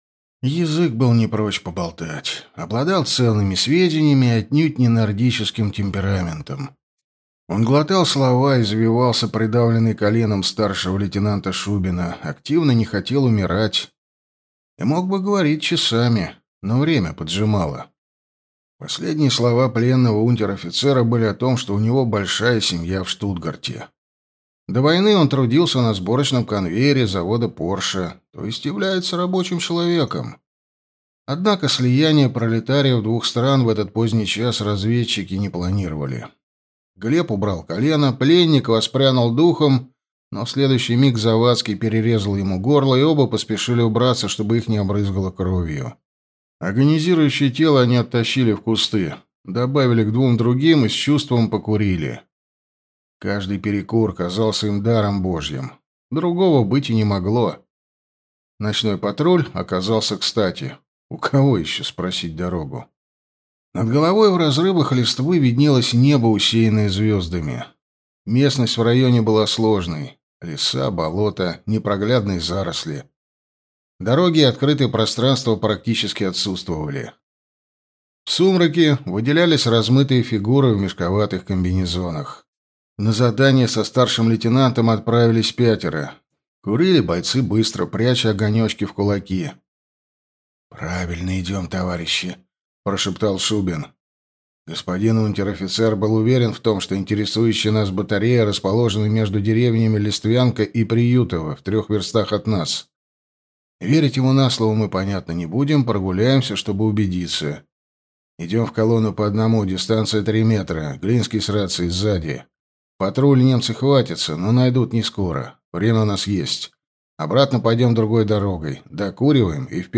Аудиокнига Генерал без армии | Библиотека аудиокниг